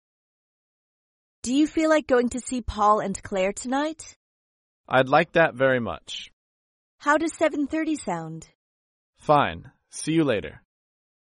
高频英语口语对话 第53期:预约一起看朋友 听力文件下载—在线英语听力室